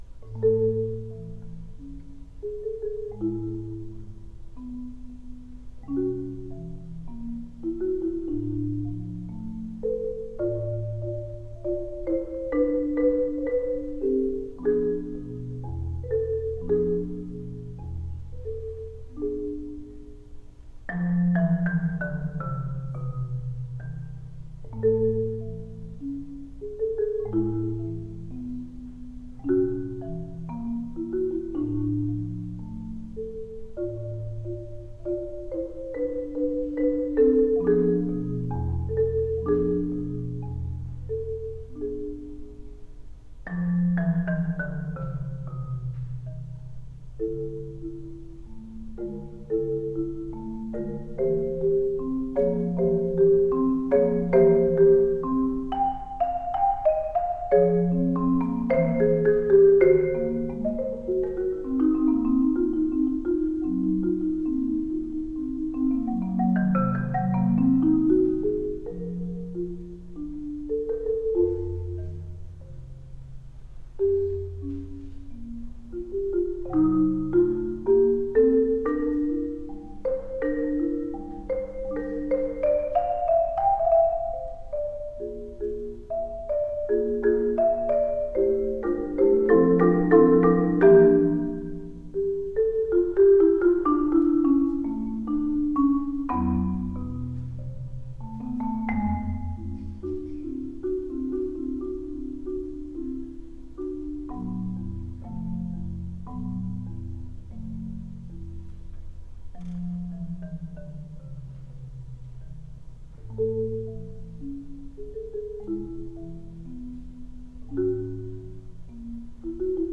Genre: Marimba (4-mallet)
Marimba (5-octave)